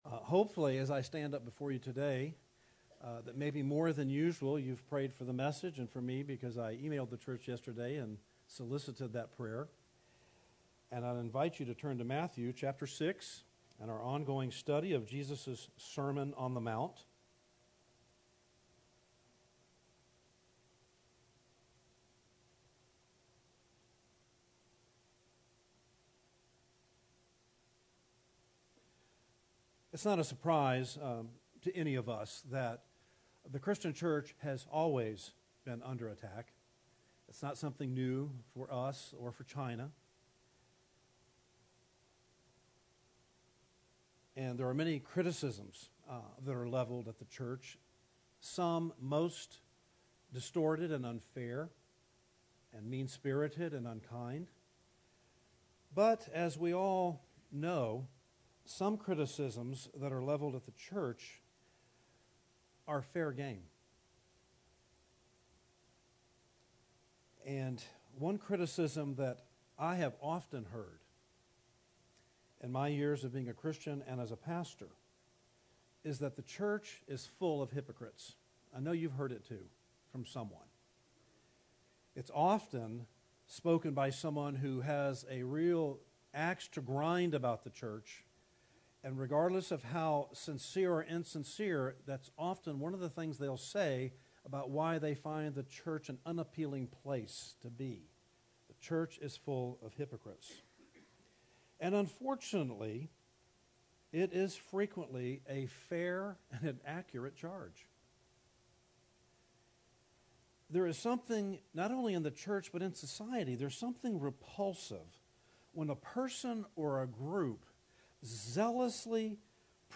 Series: Jesus' Sermon on the Mount